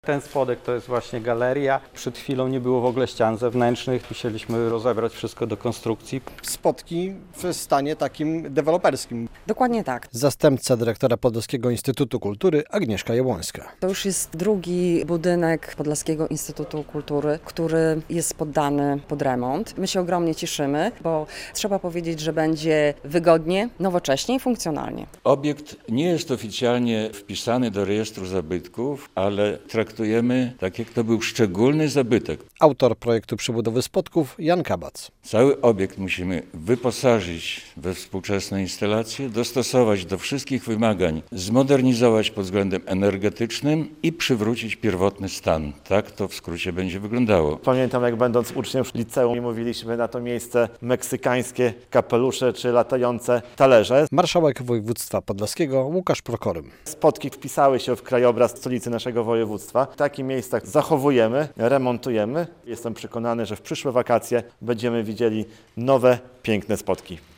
Trwa remont białostockich Spodków - relacja